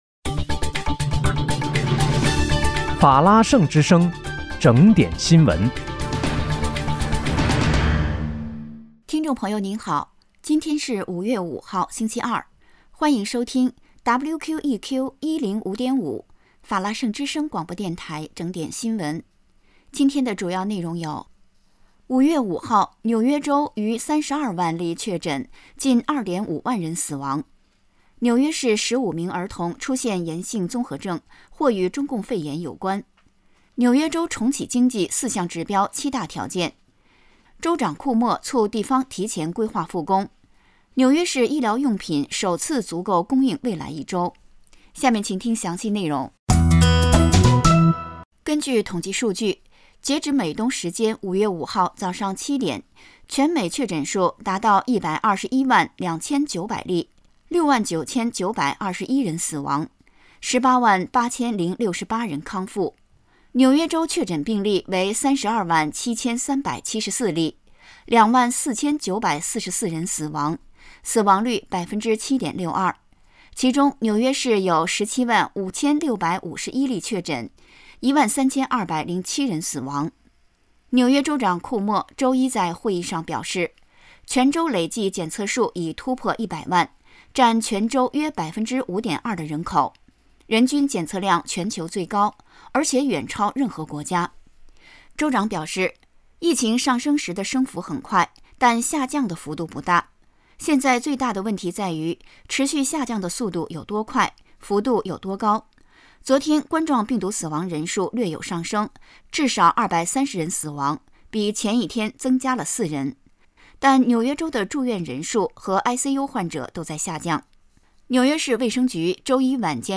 5月5日（星期二）纽约正点新闻